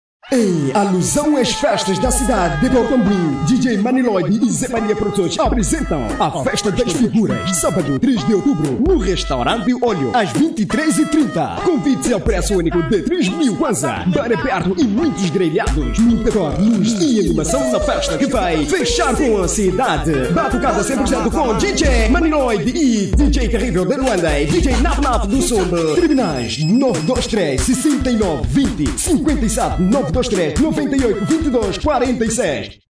Masculino
Voz Varejo 00:32